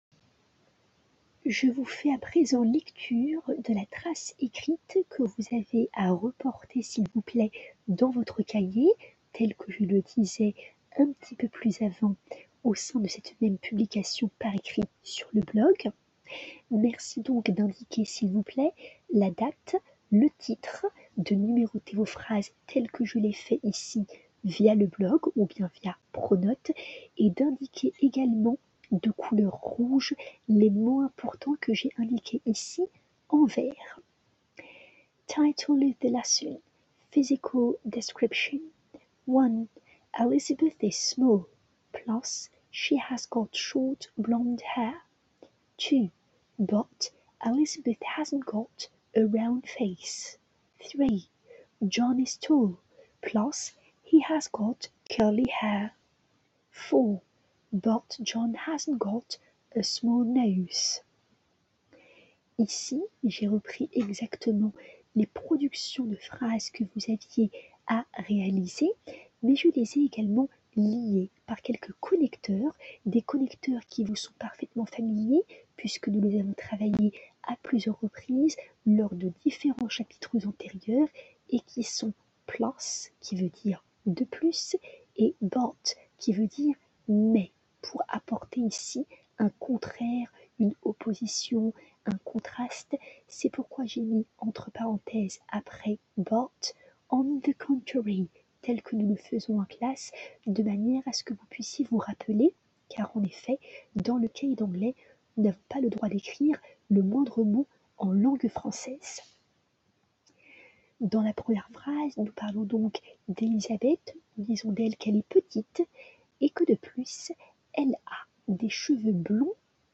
P.S. 2: veuillez m'excuser pour les petits grésillements des pistes audio proposées, s'il vous plaît: ayant rencontré un petit trouble technique avec l'enregistreur MP3 habituel, il m'a fallu disposer d'une nouvelle ressource.
Audio 3 du professeur, d'une durée de 03:23: